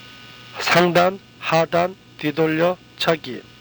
k = g i = ee Most 'a' are short vowel = ah